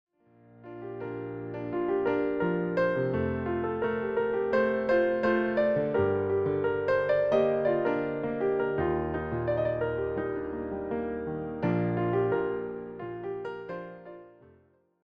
solo piano
Just calm and relaxing renditions of these well-known songs.